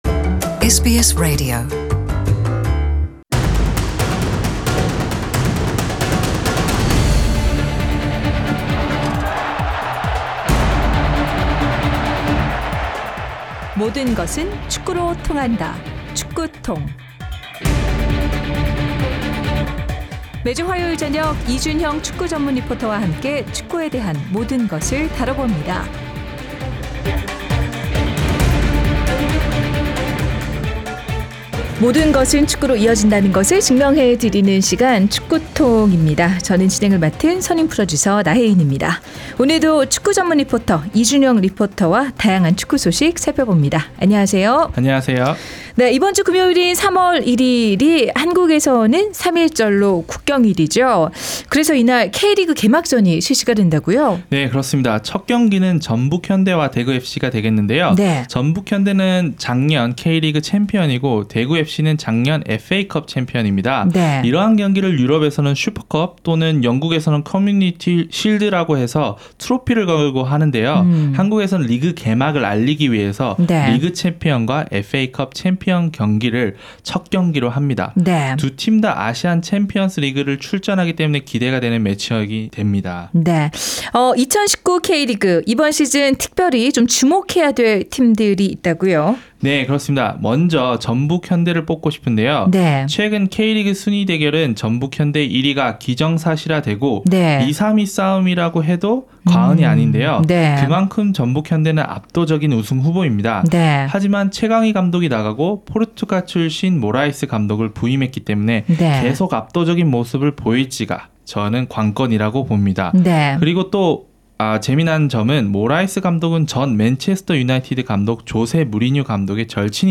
Soccer TONG is a sports segment that proves how everything leads to soccer.